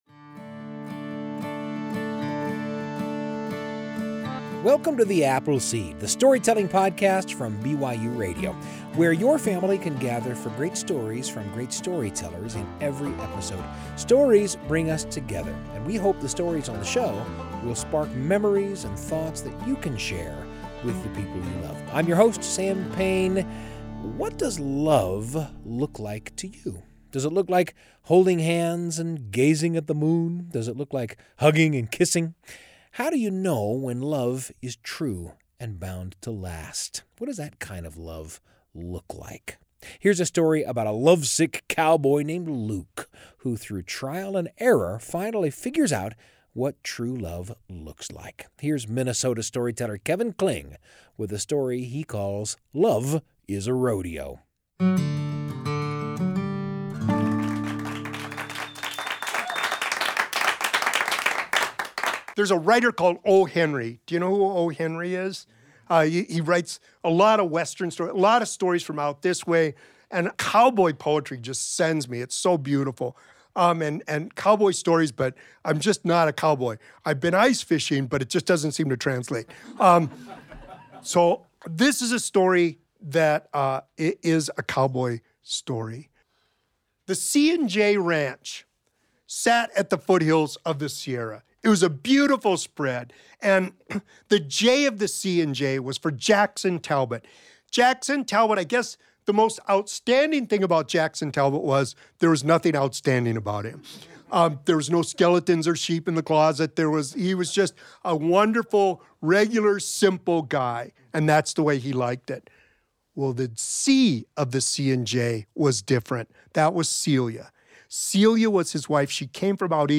Gather your family for great stories from great storytellers. Stories bring us together and the stories on The Apple Seed will spark memories that you can share with the people you love.